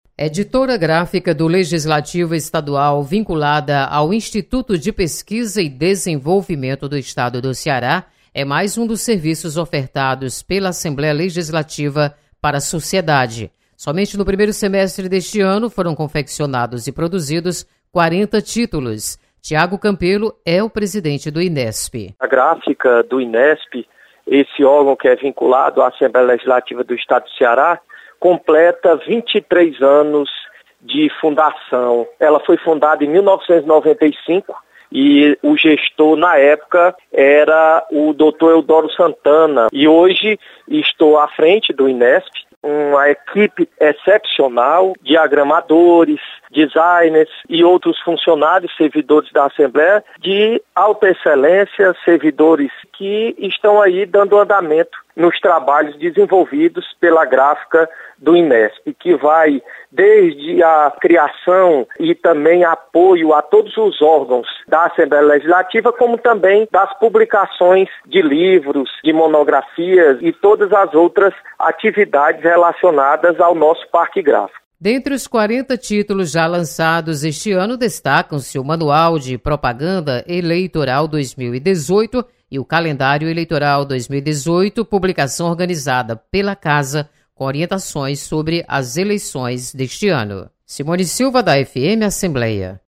Você está aqui: Início Comunicação Rádio FM Assembleia Notícias Inesp